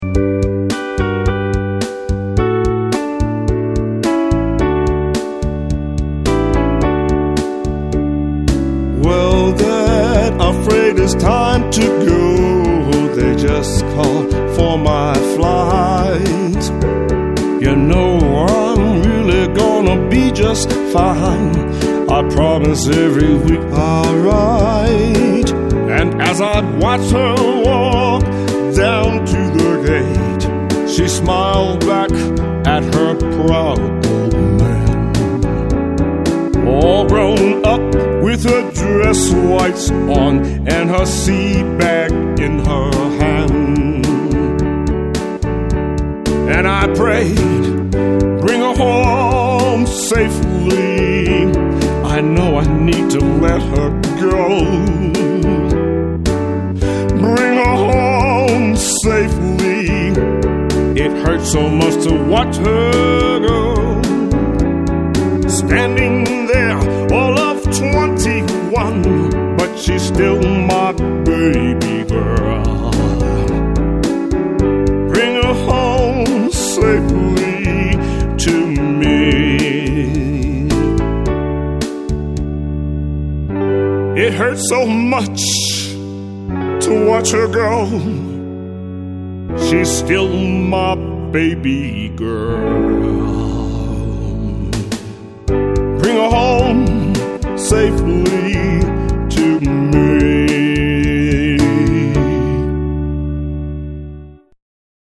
The sound clip presents the 3rd (final) verse and chorus.